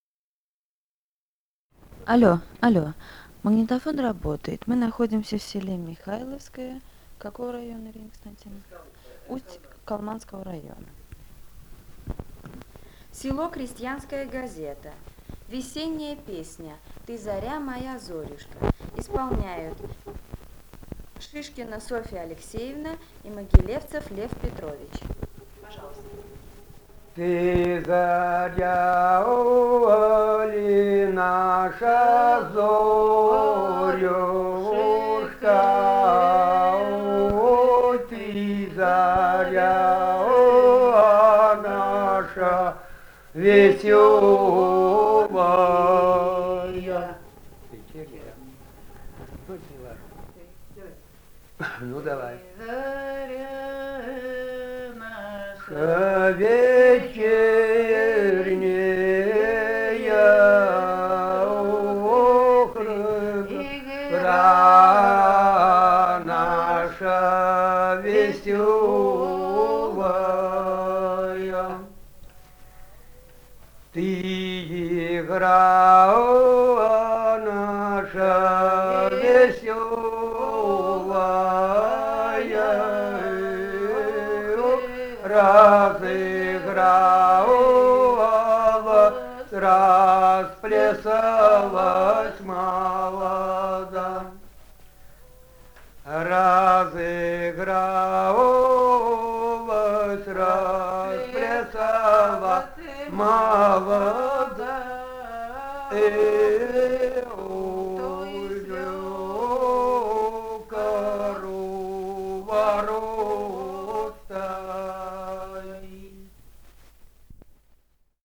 полевые материалы
«Ты заря ли, наша зорюшка» (весенняя хороводная).
Алтайский край, с. Михайловка Усть-Калманского района, 1967 г. И1001-01